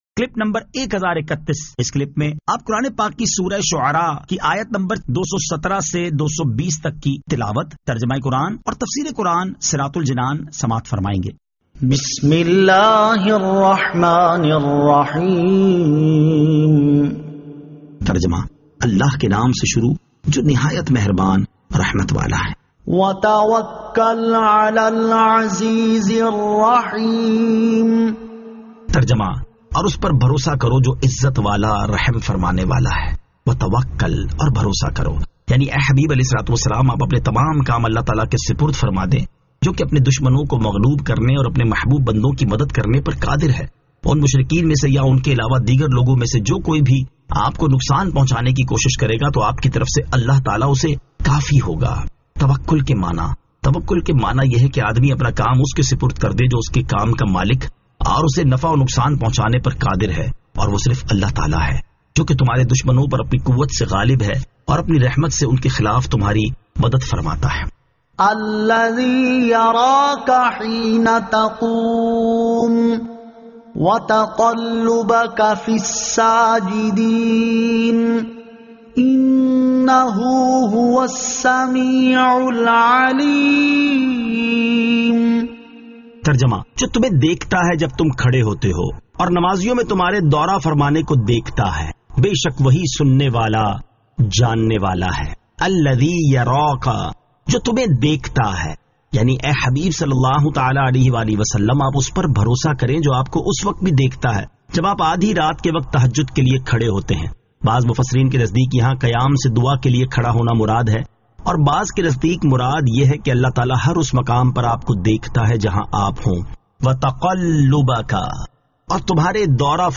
Surah Ash-Shu'ara 217 To 220 Tilawat , Tarjama , Tafseer